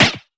stab.ogg